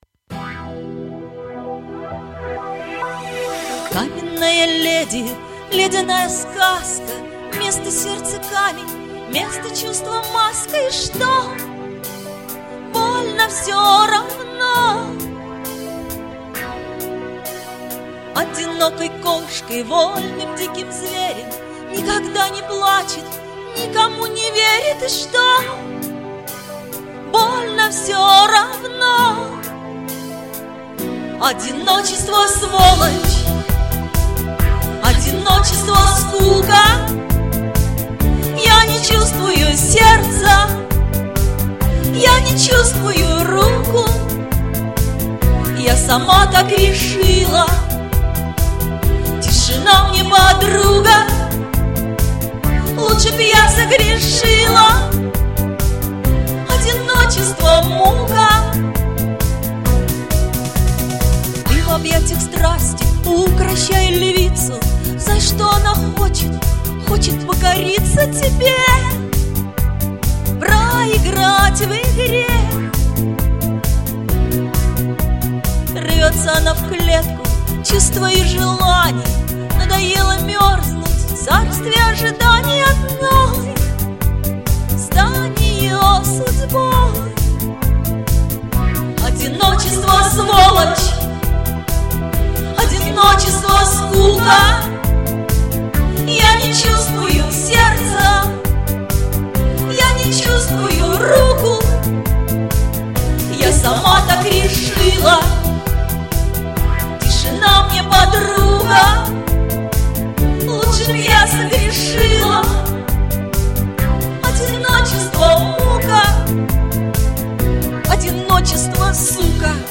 Но нельзя о СВОЛОЧИ петь нежно!
Светло спето....